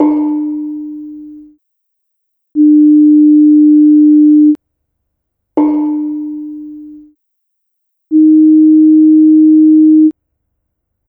Bonang Barung Sl2l of Kyai Parijata + Sine & Bonang Barung Sl2l + Sine + 60 cents